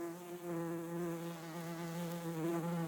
Fly_bug_idle.ogg